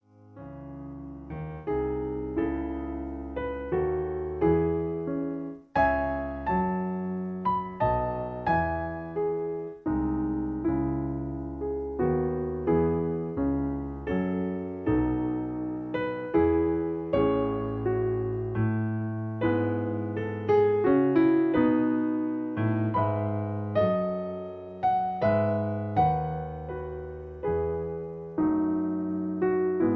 In C sharp. Piano Accompaniment